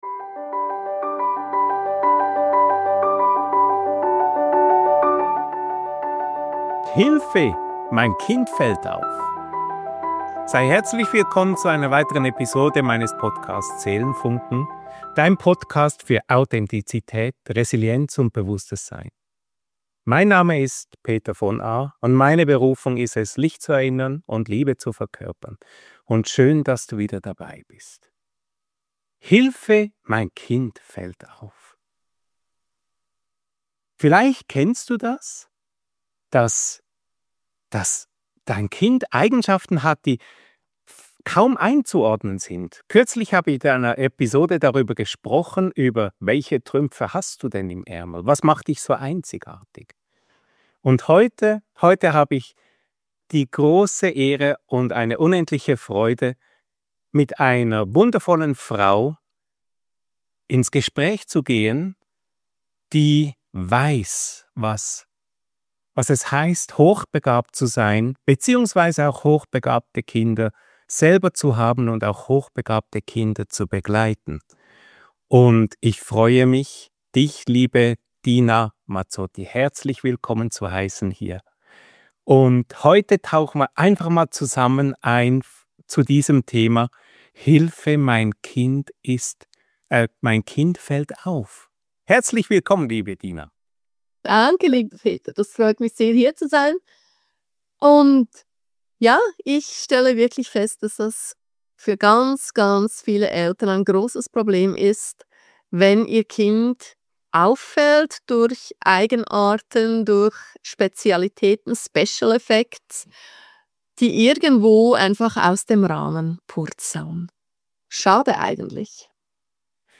Hochbegabte Kinder erkennen, fördern und lieben: Ein Gespräch